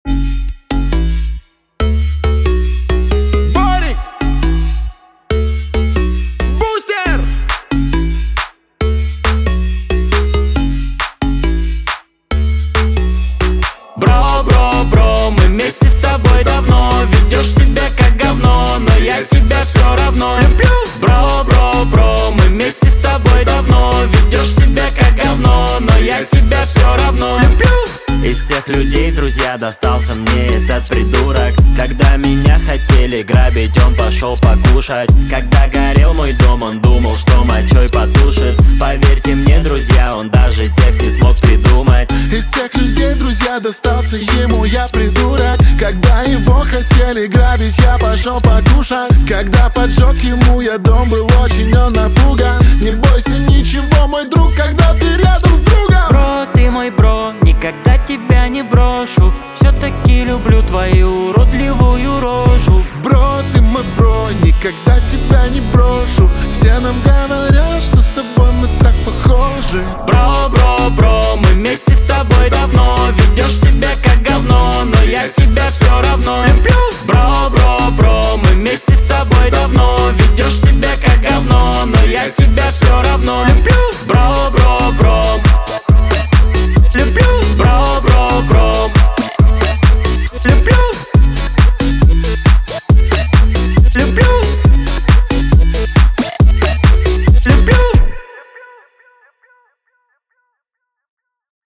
Качество: 320 kbps, stereo
Рэп